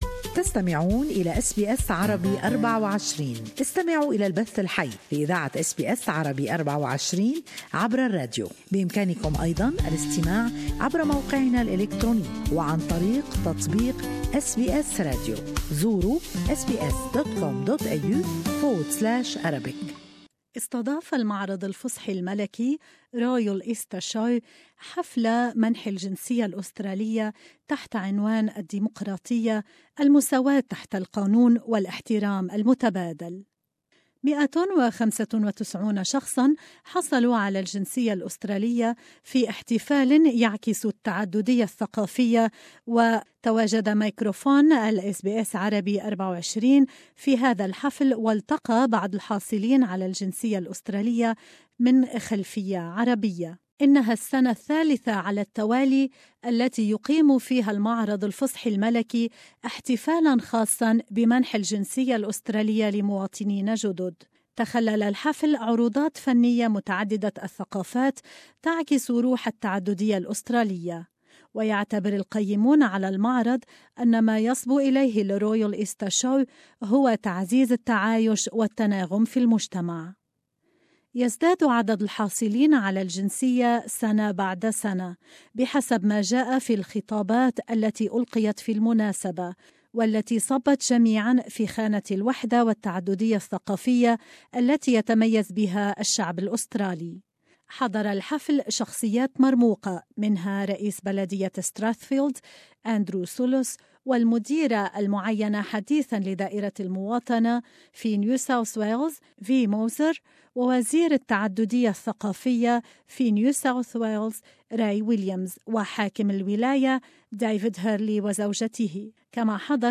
مئة وخمسة وتسعون شخصا حصلوا على الجنسية الأسترالية في احتفال مميز استضافه المعرض الفصحي الملكي في الحادي عشر من نيسان(أبريل) الحالي. تواجد ميكروفون الSBS Arabic 24 في هذا الحفل والتقى بعض الحاصلين على الجنسية الأسترالية من خلفية عربية .